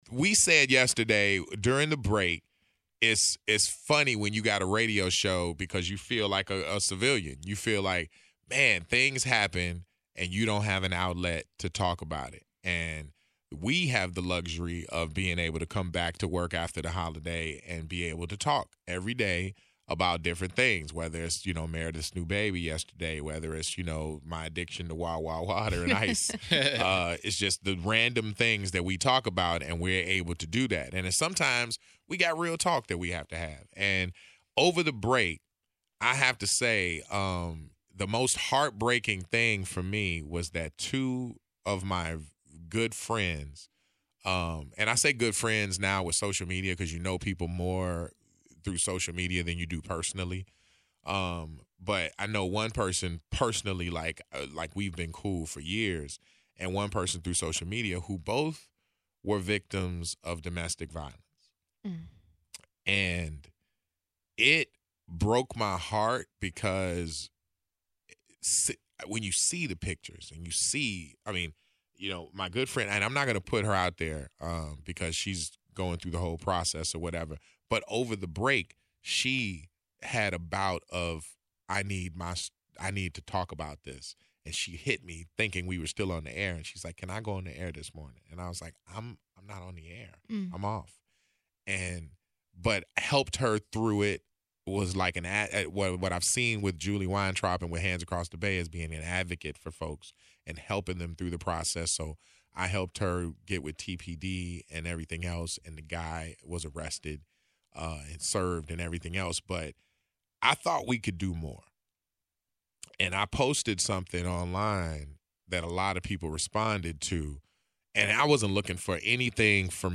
The conversation was real and raw which is a flip from the otherwise funny format. In addition they opened up the phone lines for listeners to share their experience as well.